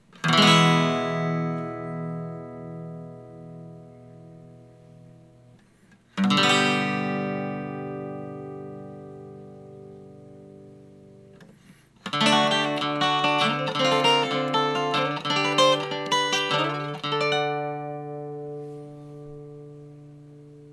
All recordings in this section were recorded with an Olympus LS-10. The amp is an Axe-FX Ultra set as outlined below. The speaker is a QSC K12. All guitar knobs are on 10 for all recordings.
1984 X-500 Acoustic
I recorded them as I would an acoustic guitar but sitting with the guitar on my lap and my Olympus LS-10 sitting on the table in front of me point directly at the neck pickup of the guitar.